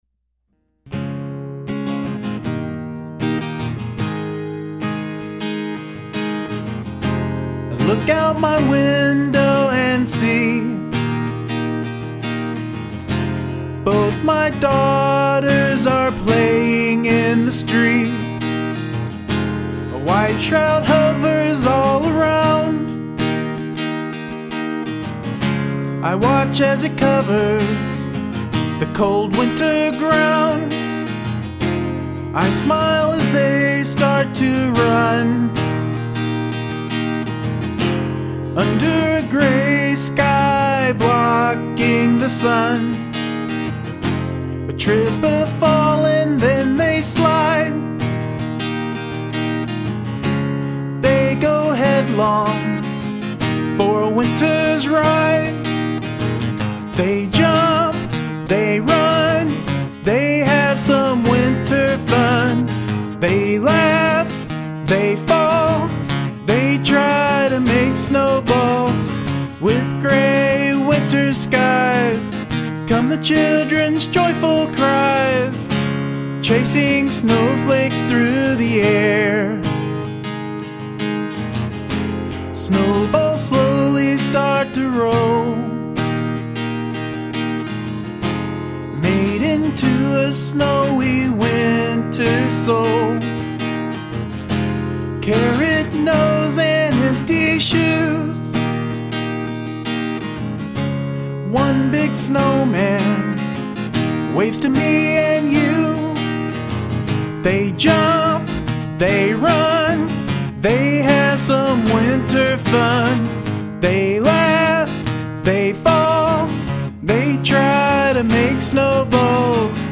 This is a nice happy song! Well done!
I like the Vibe of this One!
You Sound Happy!
upbeat song!
Very simple/yet powerful song.